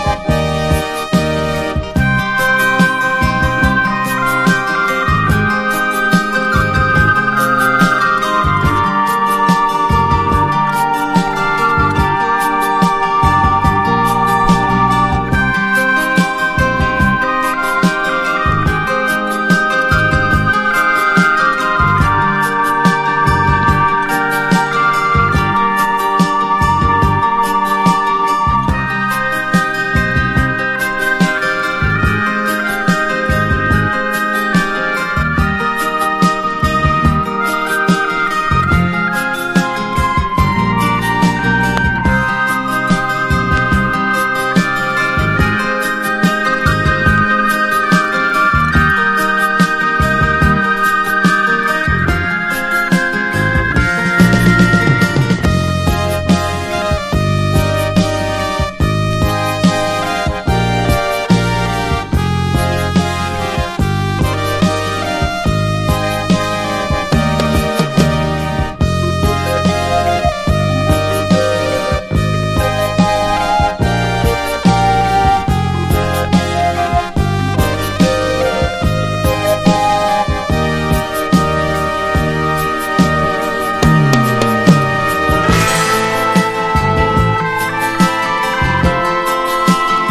1. 70'S ROCK >
大味でスペーシーなシンセがメインに据えられた、幻想的な叙情サウンドの楽曲たち。ヨーロッパ独特の郷愁も感じられる1枚。
PSYCHEDELIC / JAZZ / PROGRESSIVE